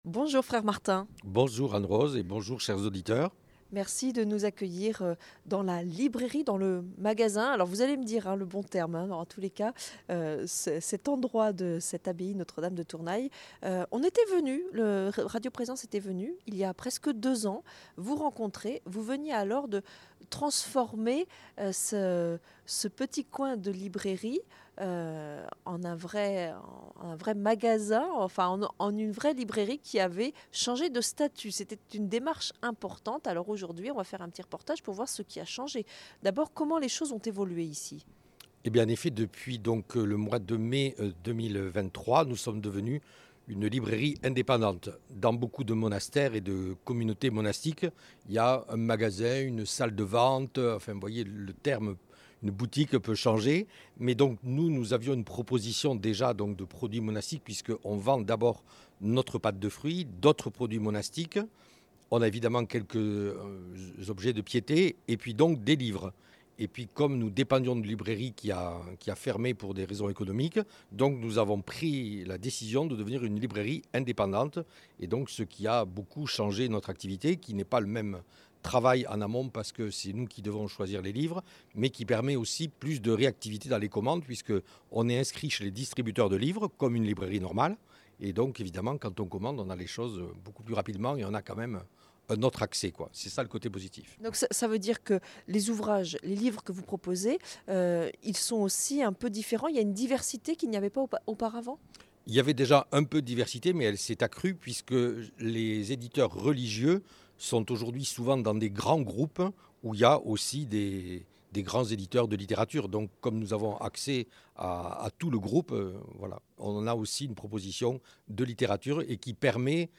En direct, depuis l’abbaye bénédictine de Tournay dans les Hautes-Pyrénées.